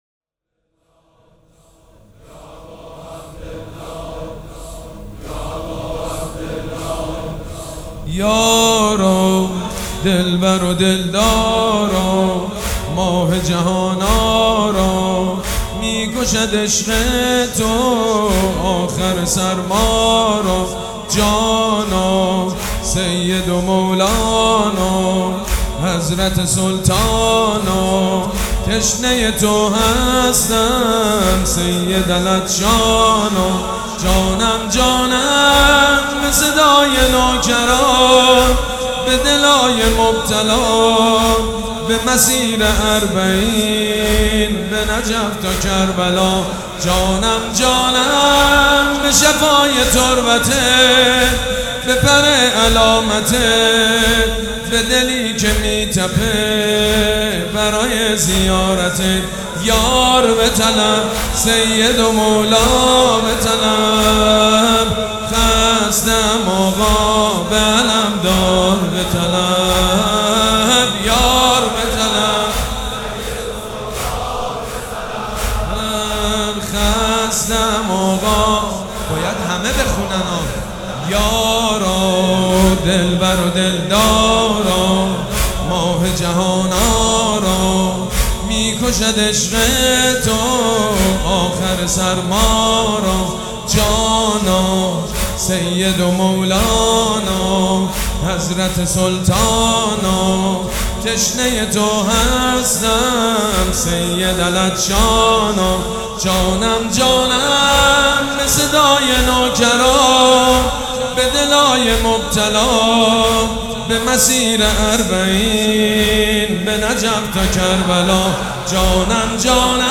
مداح
حاج سید مجید بنی فاطمه
مراسم عزاداری شب سوم